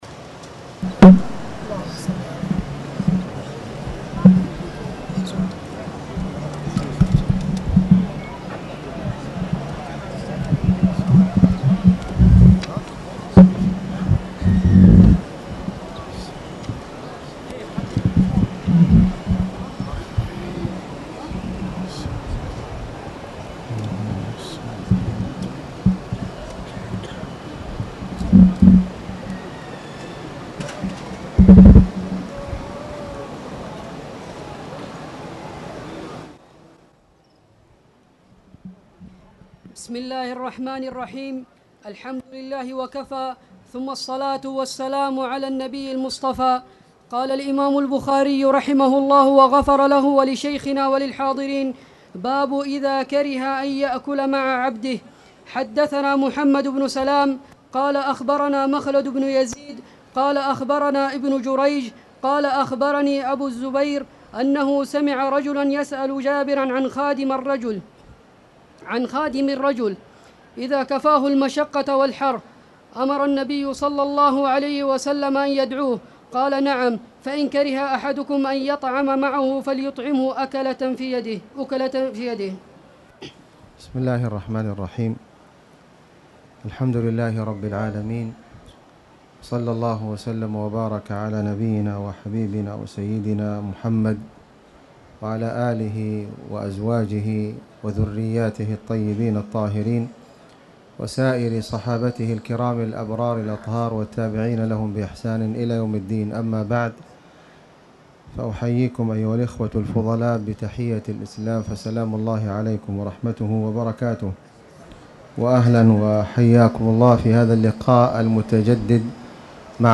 تاريخ النشر ٥ ذو القعدة ١٤٣٧ هـ المكان: المسجد الحرام الشيخ: خالد بن علي الغامدي خالد بن علي الغامدي باب إذا كره أن يأكل مع عبده - باب العبد راعٍ